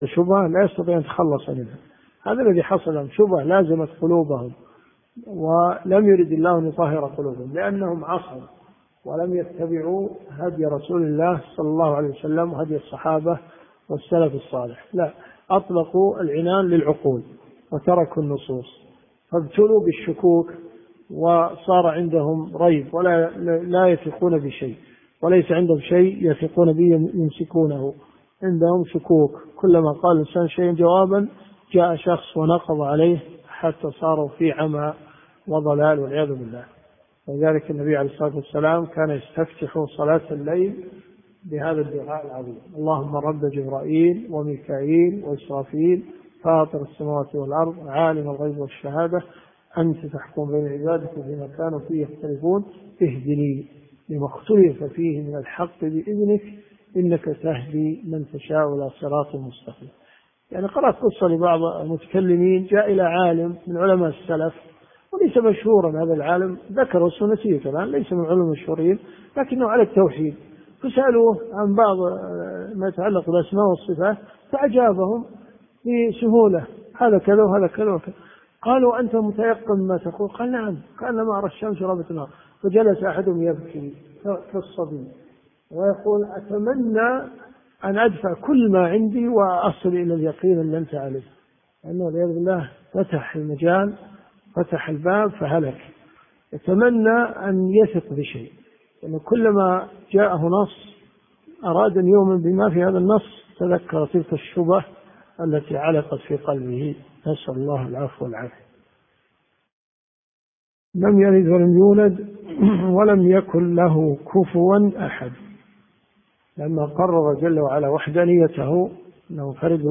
الدروس الشرعية
دروس صوتيه ومرئية تقام في جامع الحمدان بالرياض